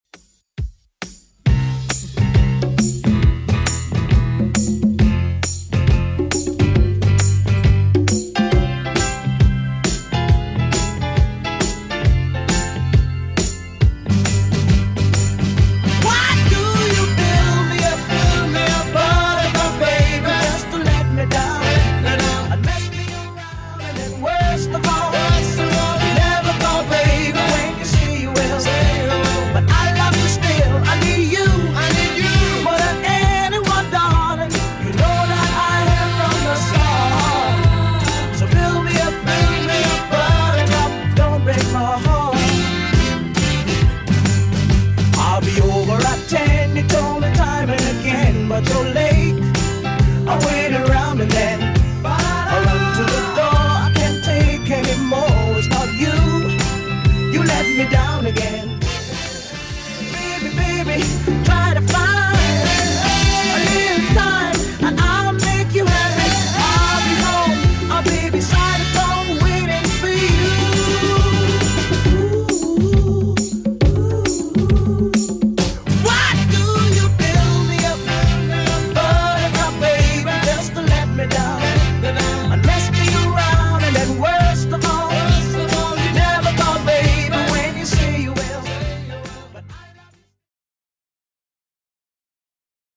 Genre: 80's
BPM: 124